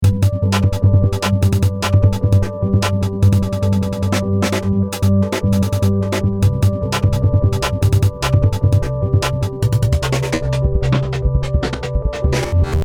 Drums modified to sound more appropriate. Loop is spliced up and decays are diminished to produce short, stabbing drum sounds. Some are repeated quickly to create buzzing rolls.